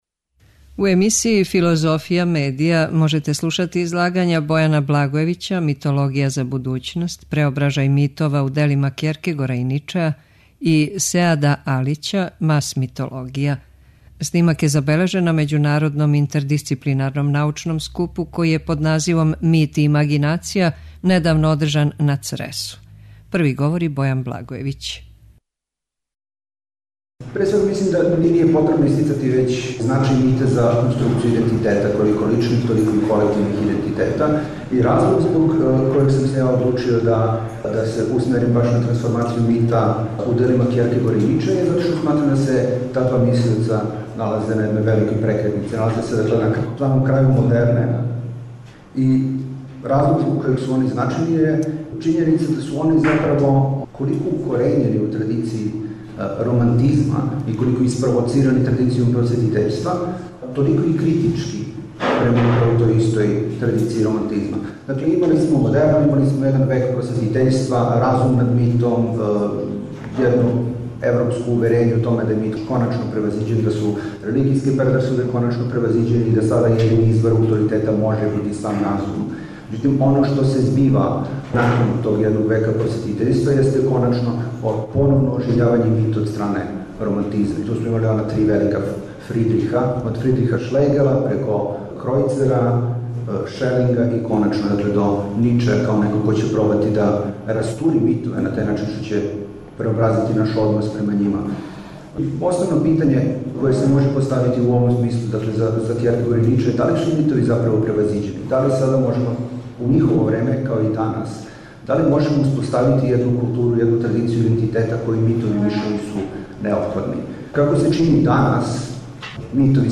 Снимак је забележен на Међународном интердисциплинарном научном скупу који је под називом „Мит и имагинација” недавно одржан на Цресу.